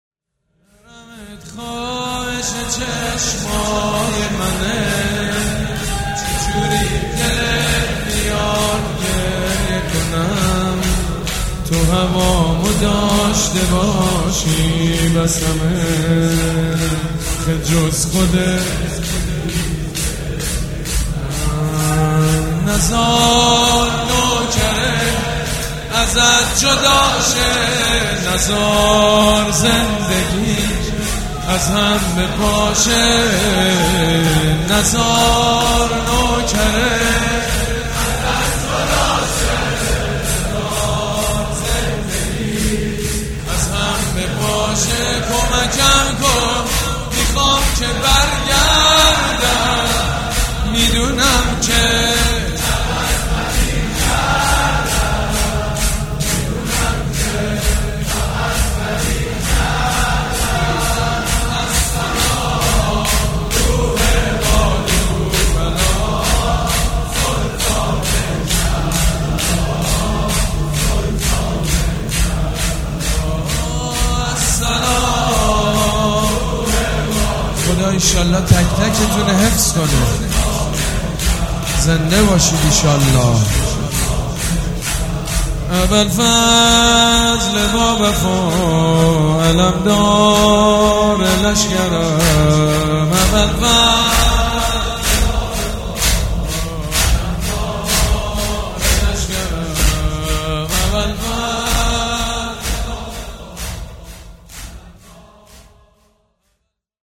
«شهادت امام صادق 1397» شور: حرمت خواهش چشمای منه